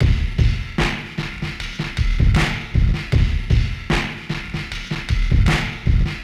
pigs77bpm.wav